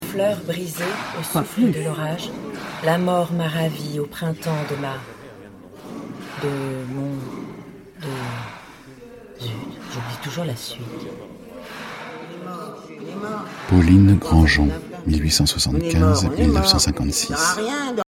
Fiction